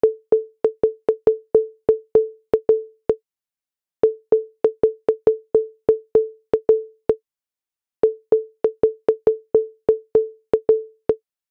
You can get some nice shepard tones with these ugens, which is just glisson synthesis with a frequency trajectory per grain (mess with the freqModOffset for some different flavours).